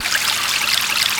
STREAM.WAV